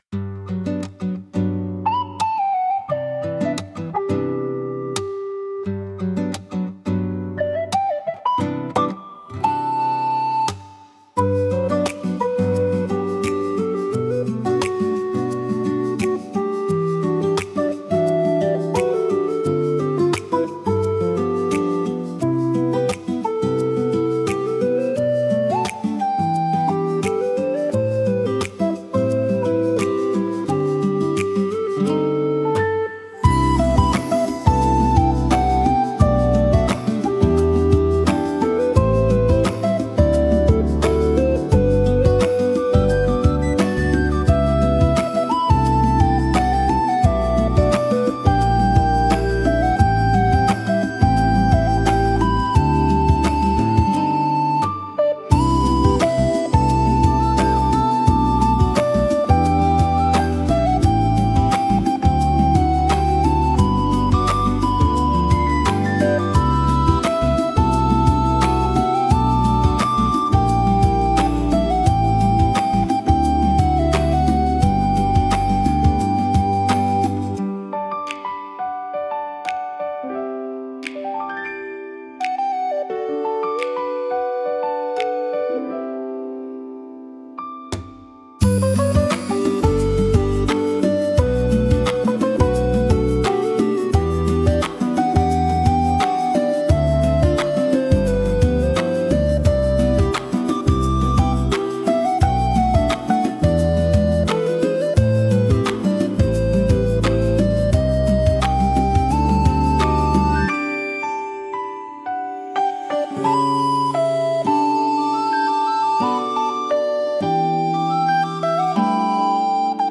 BGMセミオーダーシステムほのぼのは、リコーダーを主体にした和やかで穏やかな楽曲です。
柔らかく優しい音色が心を落ち着かせ、自然でゆったりとした雰囲気を醸し出します。